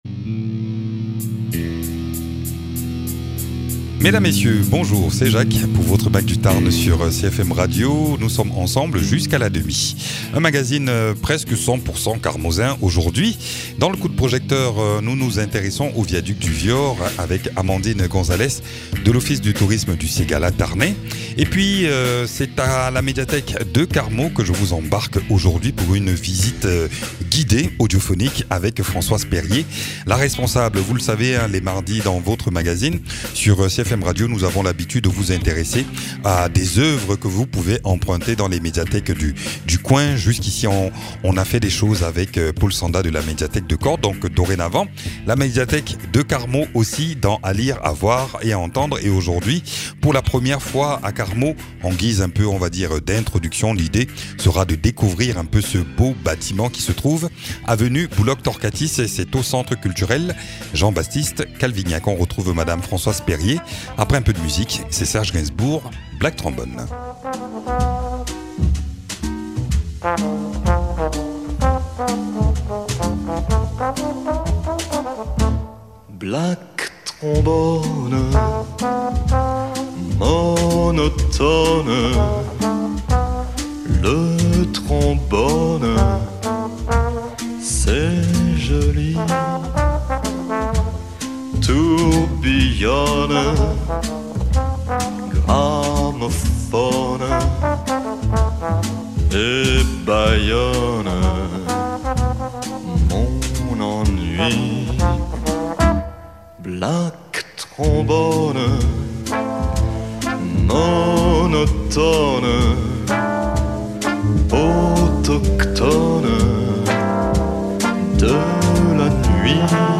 La porte franchit, visite audiophonique du lieu et informations sur le fond documentaire, les actions et conditions d’inscription. Et puis nous allons dans la vallée du Viaur pour un focus sur l’un des trois ouvrages métalliques les plus importants du XIXe siècle en France avec le Viaduc de Garabit et la Tour Eiffel.